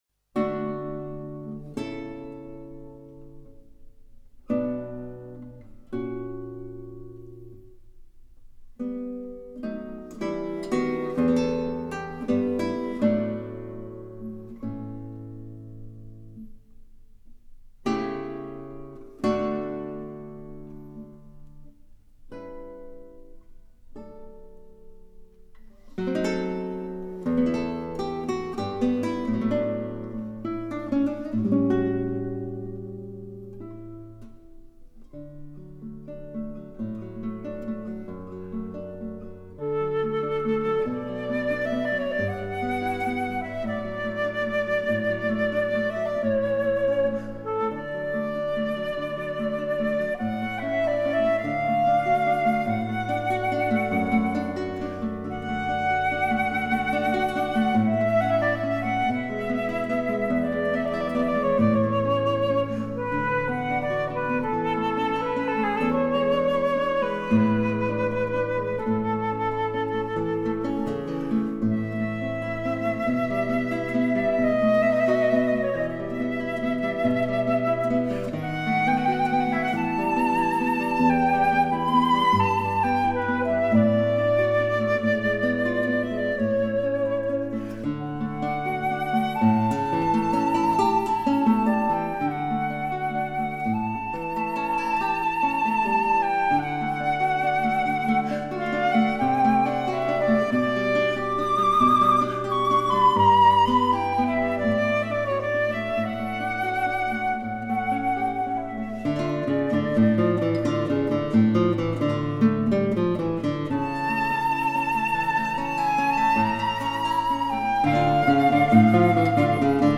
小夜曲（Serenade）